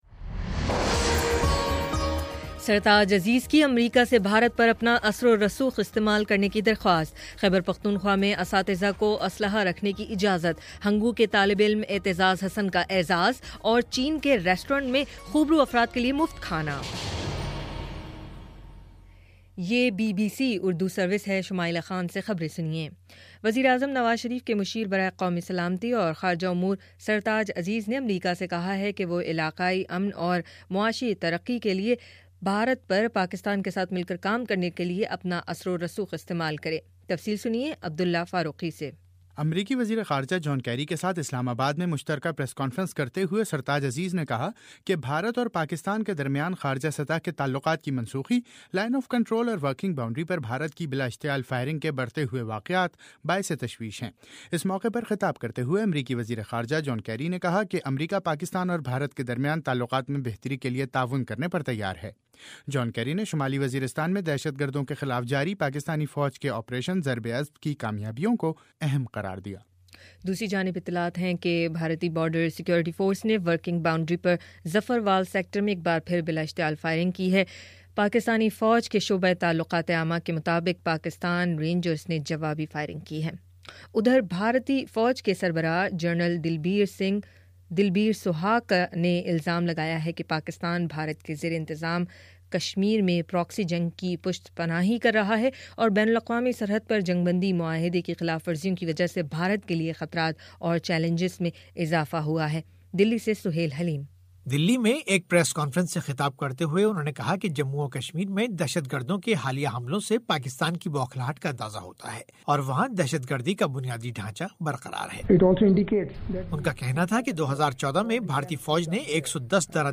جنوری 13: شام سات بجے کا نیوز بُلیٹن